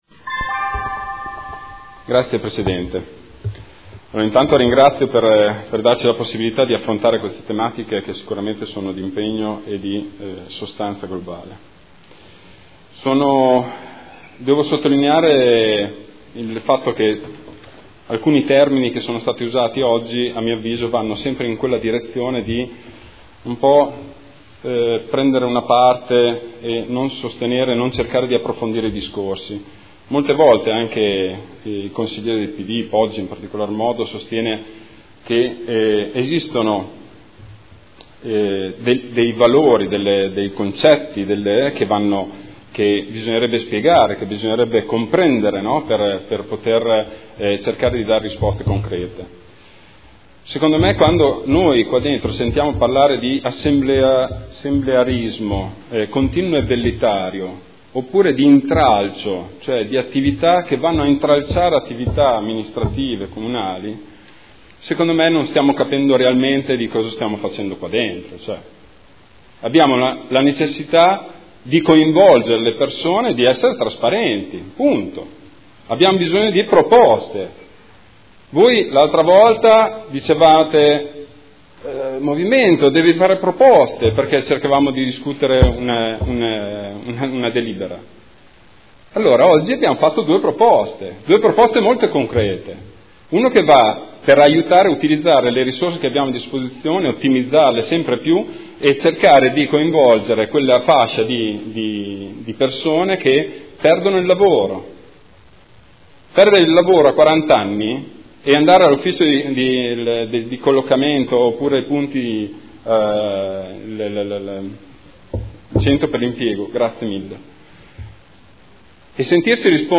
Seduta del 20/11/2014. Dibattito su Ordini del Giorno e Mozione aventi per oggetto "Patto per Modena"